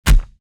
body_hit_small_20.wav